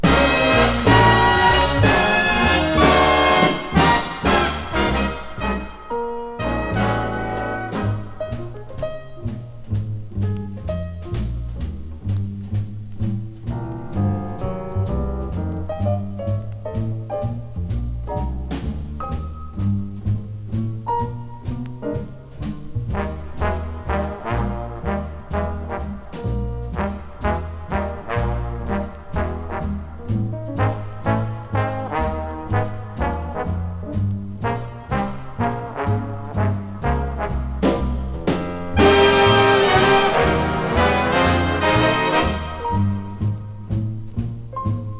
楽しく、ダイナミック、そして美しさもある1957年ニューヨーク録音。
Jazz, Swing, Big Band　France　12inchレコード　33rpm　Mono